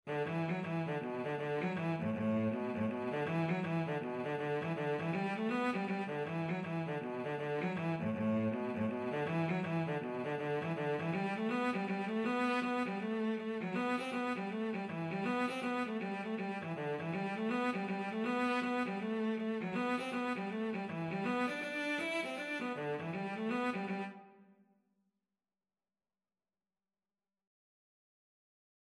Cello version
4/4 (View more 4/4 Music)
A3-E5
G major (Sounding Pitch) (View more G major Music for Cello )
Cello  (View more Easy Cello Music)
Traditional (View more Traditional Cello Music)